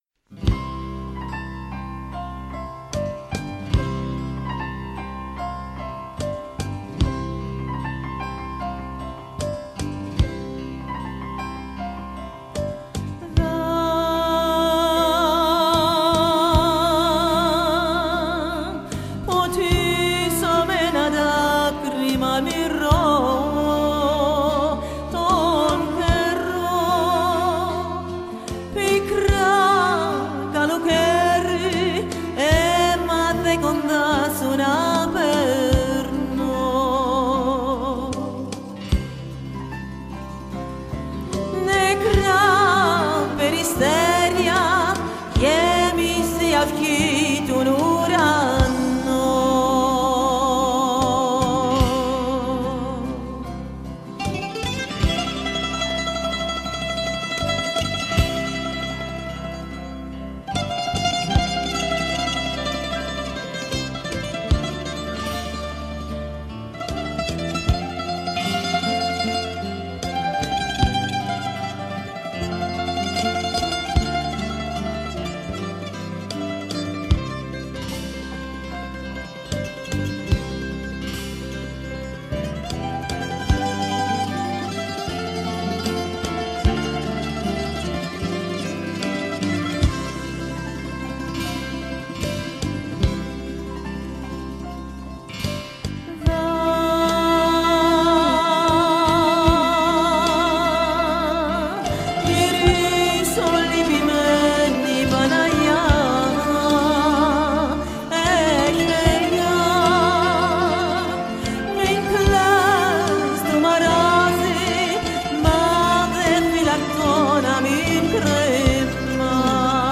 zelf eens horen zingen, bijvoorbeeld een prachtig Grieks lied?